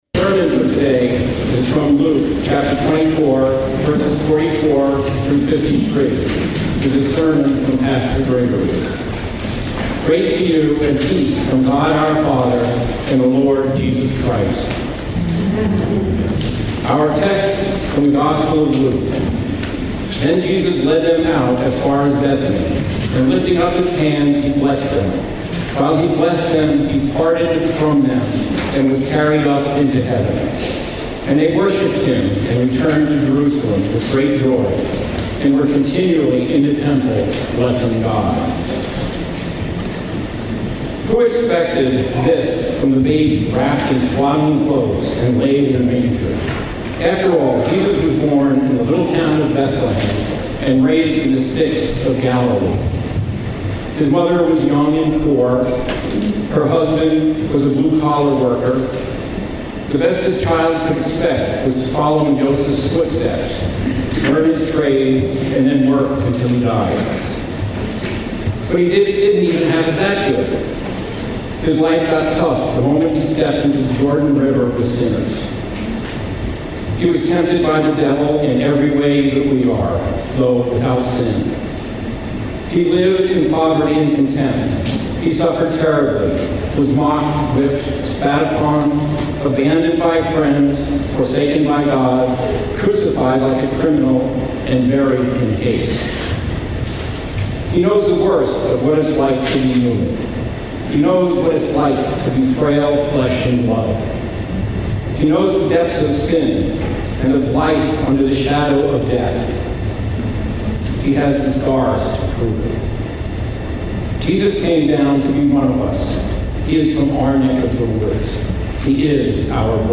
Passage: Luke 24:44–53 Service Type: The Feast of the Ascension of Our Lord